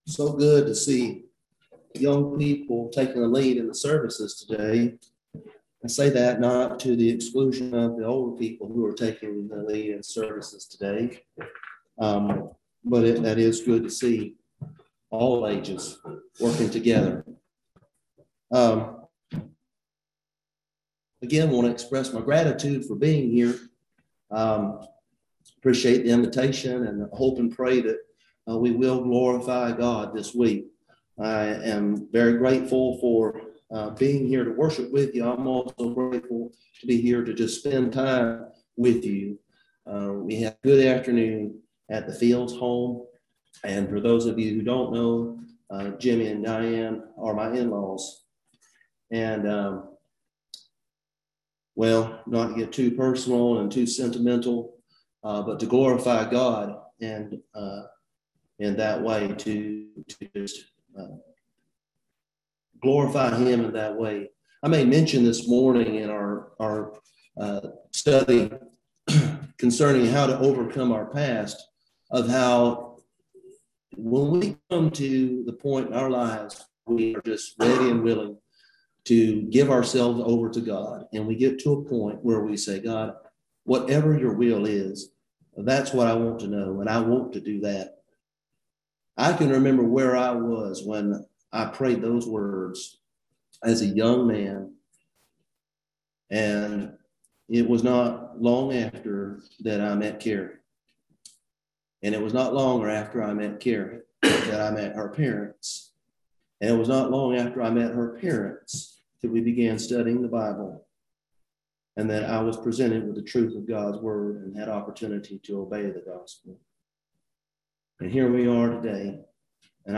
Passage: Revelation 2:8-11 Service Type: Gospel Meeting